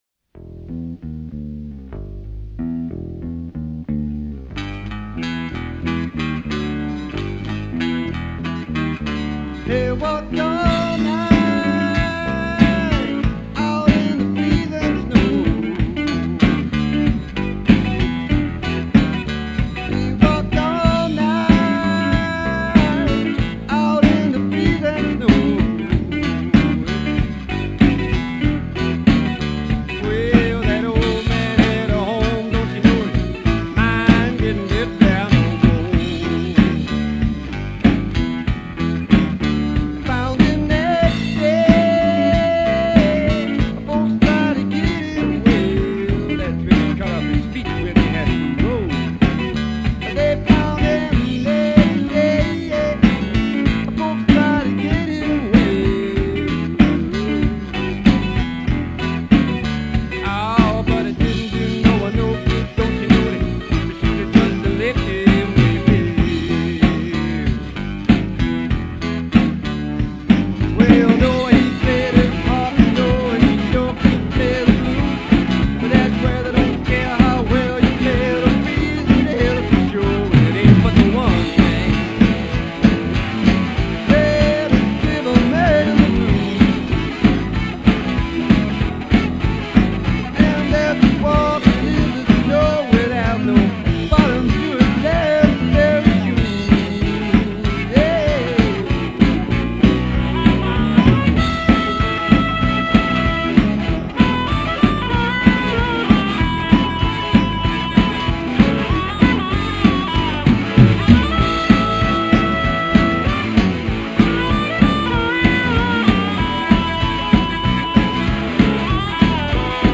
blues band
drums
vocals/harp